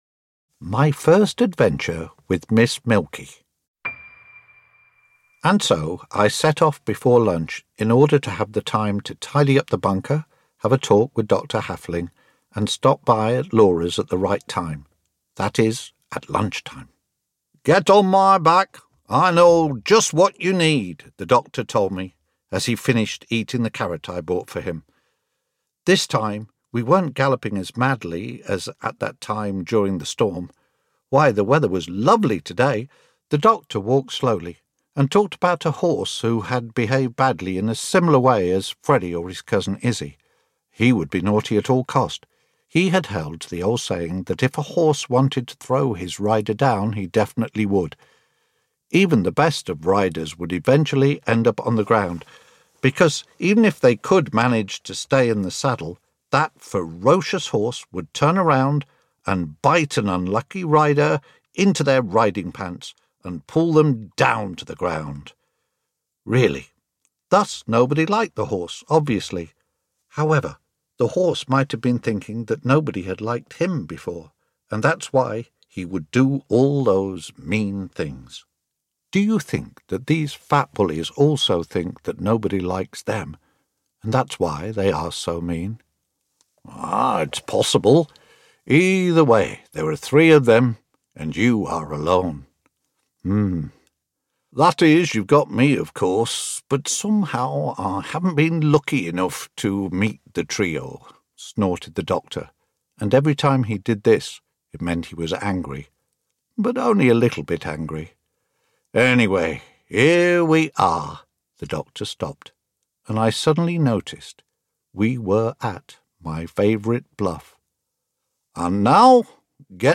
Three Friends and the Totally Fantastic Bunker audiokniha
Ukázka z knihy